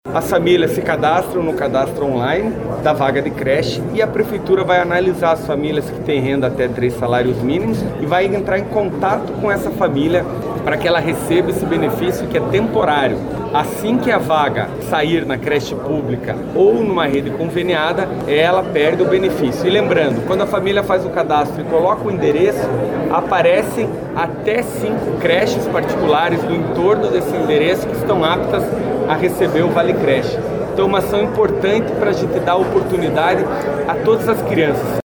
O prefeito Eduardo Pimentel explicou como vai funcionar o programa e ressaltou que o benefício não pode ser destinado para outros fins.